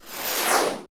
laser2.wav